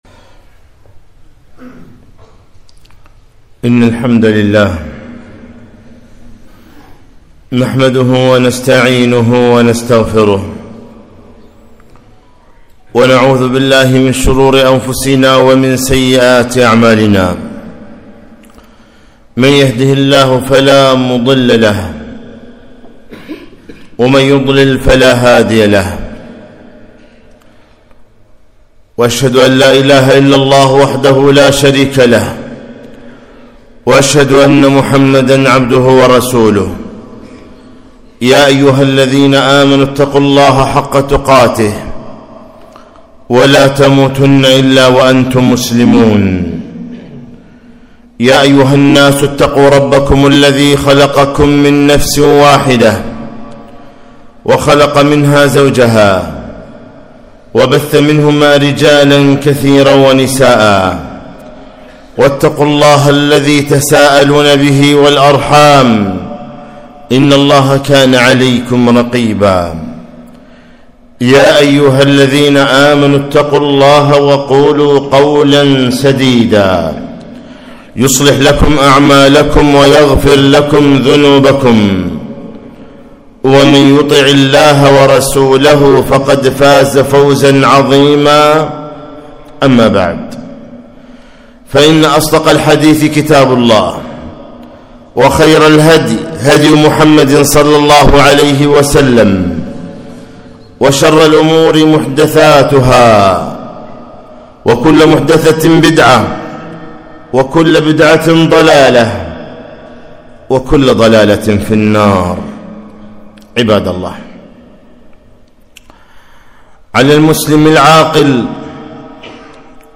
خطبة - الافتقار إلى الله